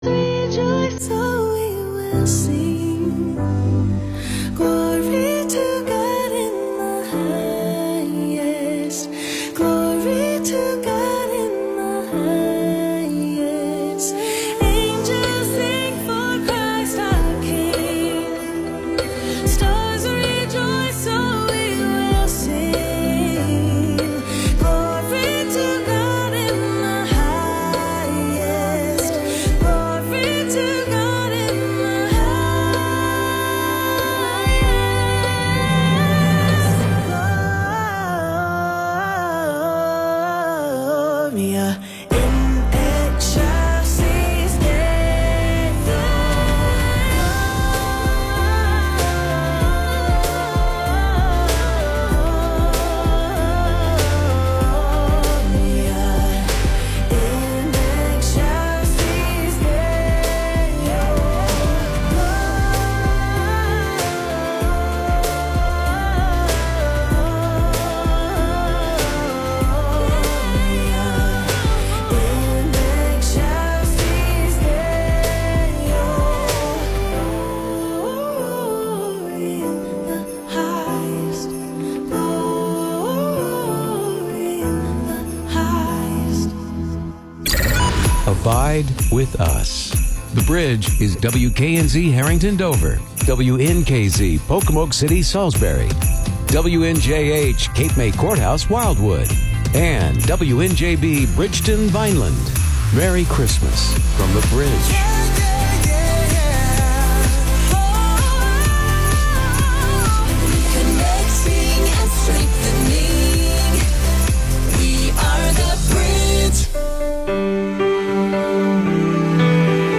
Upbeat, encouraging, humorous, and fun conversations with good friends!